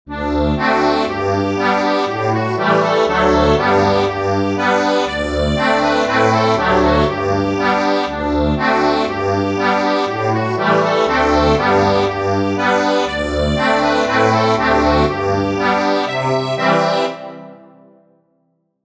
Instrument: accordion